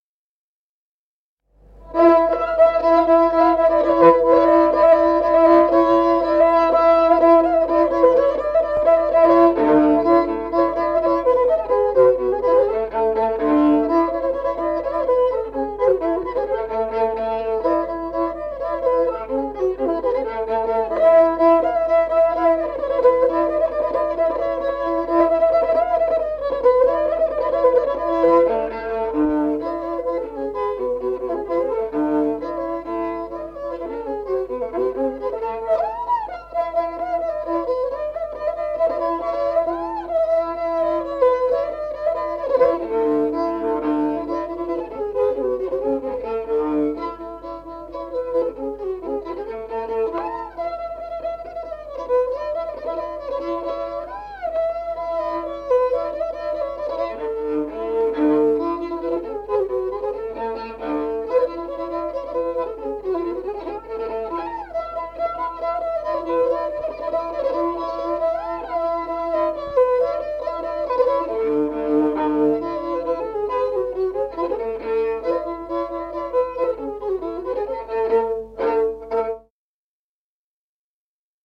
Музыкальный фольклор села Мишковка «Сербиянка», репертуар скрипача.